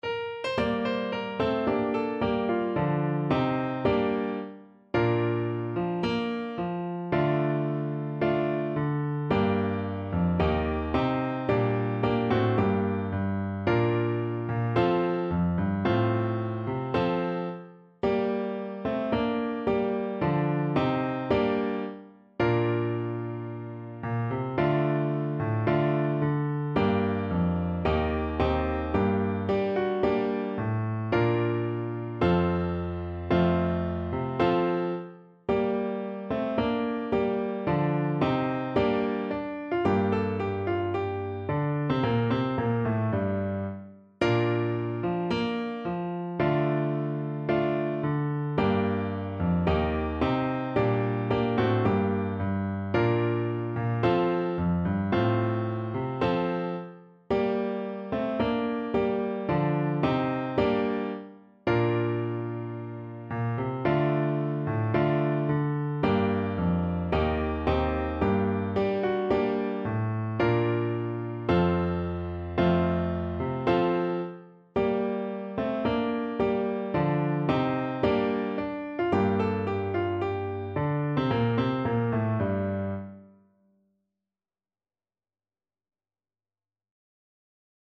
4/4 (View more 4/4 Music)
~ = 110 Allegro (View more music marked Allegro)